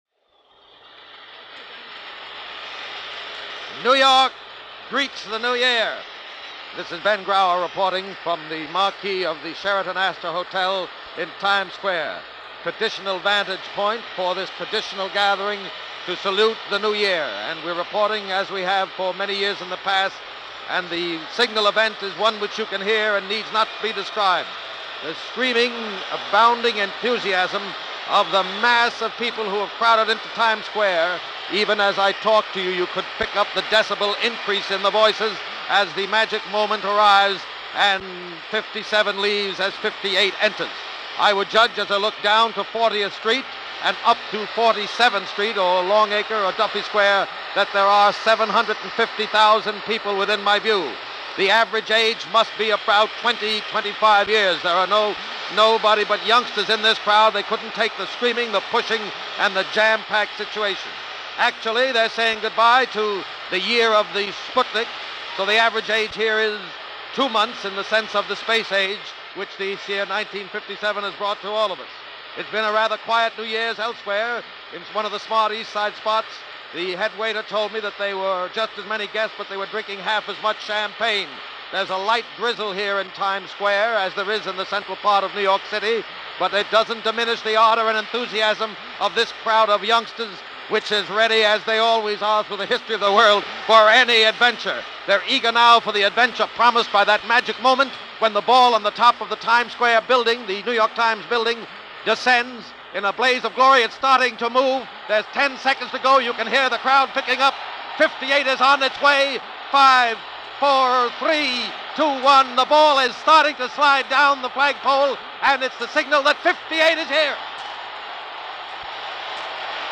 With 750,000 people cheering, throwing confetti and cracking open champagne bottles, the cacophony of happy noise, all captured in this yearly ritual on TV and Radio.
And as the first few minutes of 1958 get started, the network cuts away to Birdland and a live performance by the legendary Count Basie and his Orchestra, with Joe Williams taking the vocals.
Mostly live and unrehearsed and all celebrating the end of another hair-raising year.